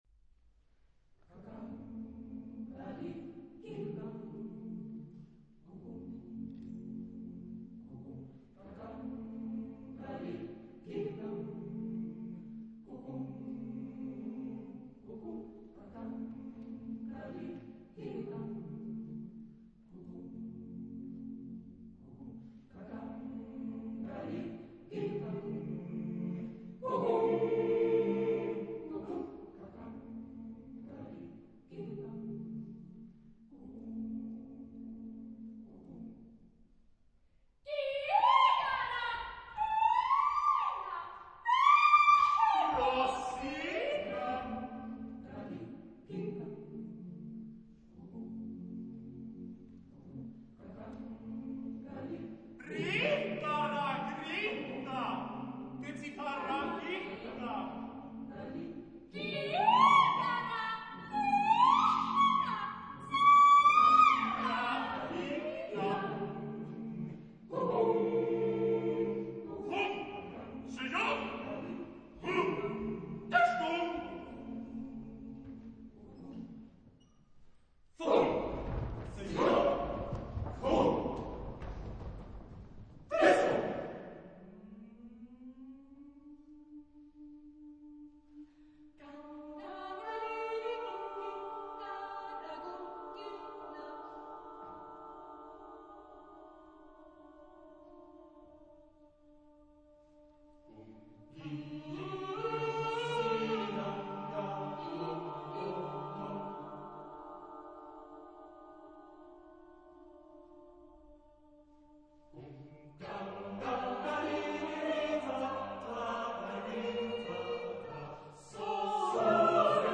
Epoque: 20th century  (1970-1979)
Genre-Style-Form: Onomatopoeia
Mood of the piece: hypnotic
Type of Choir: SATB  (4 mixed voices )